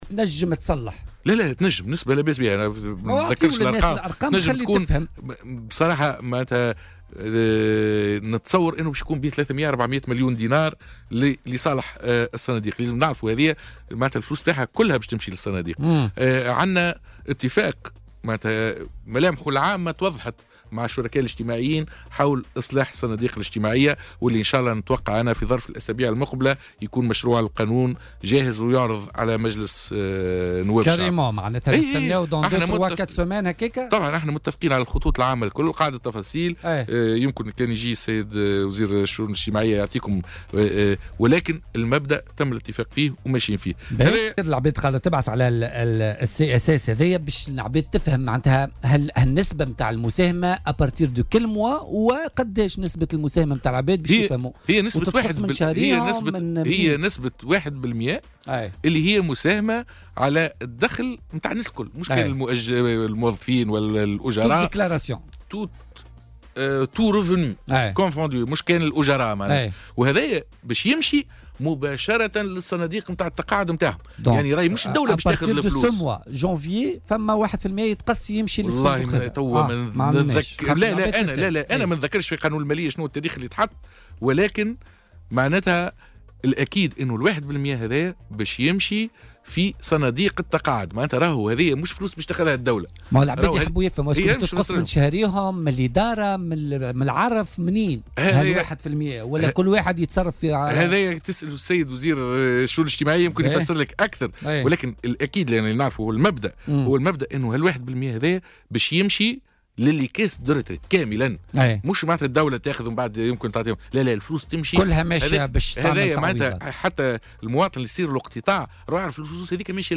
وفي ما يتعلّق بتقديرات الحكومة حول عائدات هذا الإجراء قال ضيف "بوليتيكا" على "الجوهرة أف أم"، إنها تتراوح بين 300 مليون دينار و400 مليون دينار لفائدة الصناديق الاجتماعية.